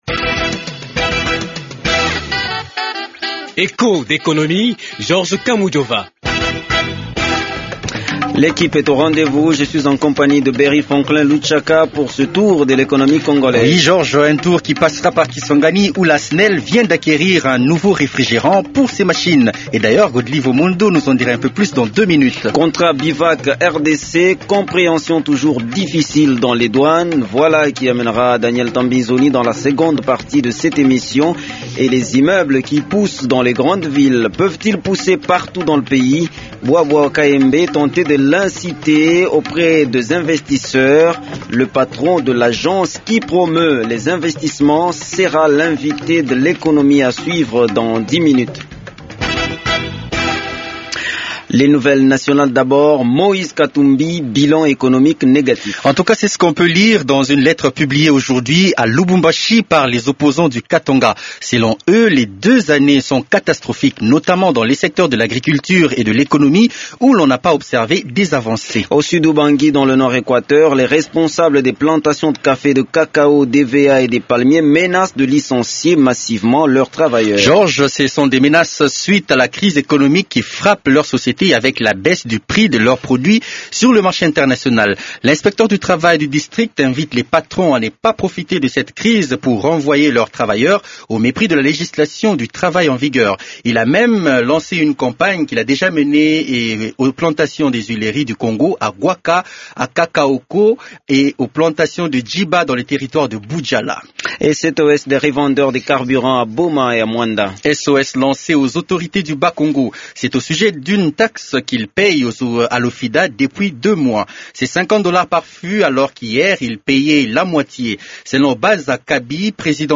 Matthias Bwabwa Wa Kayembe, patron de l’Agence Nationale pour la Promotion des Investissements(ANAPI) est l’invité de l’économie. L’émission fait un passage a Kisangani ou la SNEL vient de se doter d’un refrigerant, une solution aux problemes de delestages intensifs qui paralysent les activités commerciales. Echos d’économie s’arrête sur le contrat BIVAC-RDC dont la compréhension demeure difficile dans les différentes douanes du pays.